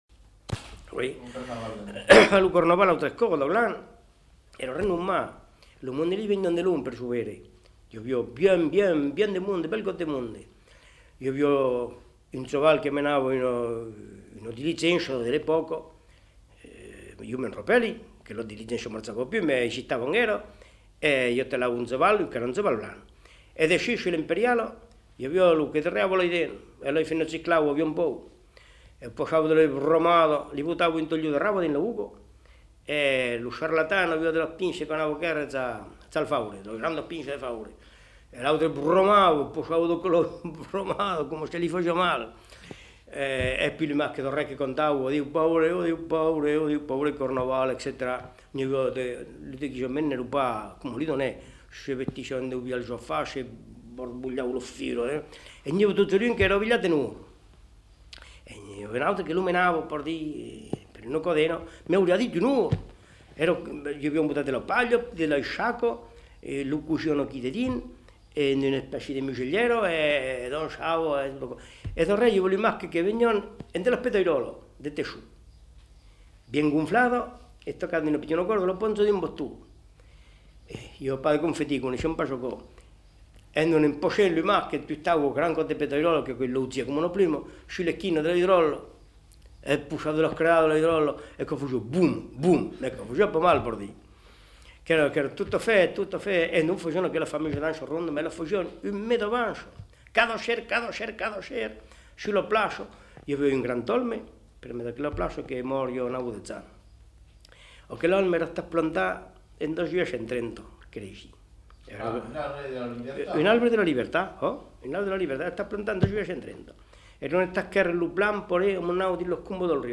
Aire culturelle : Périgord
Lieu : Daglan
Genre : témoignage thématique